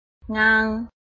拼音查詢：【詔安腔】ngang ~請點選不同聲調拼音聽聽看!(例字漢字部分屬參考性質)